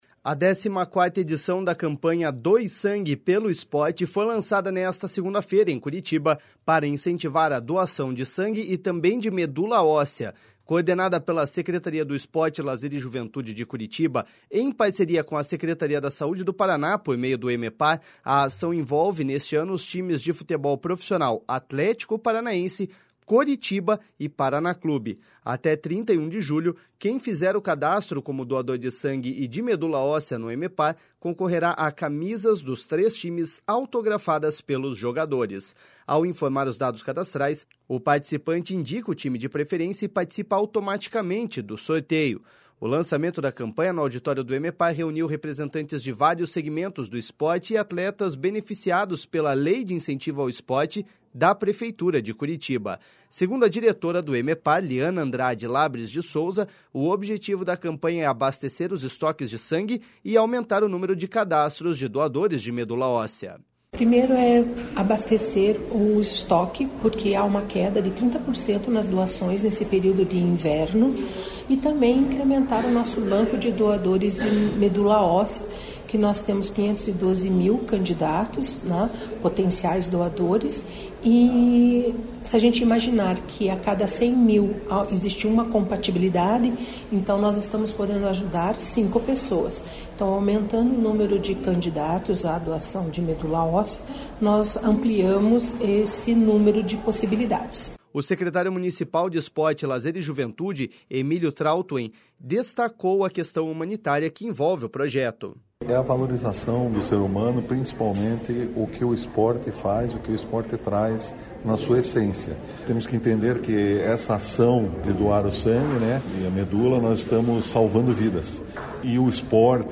O secretário Municipal de Esporte, Lazer e Juventude, Emílio Trautwein, destacou a questão humanitária que envolve o projeto.// SONORA EMILIO TRAUTWEIN.//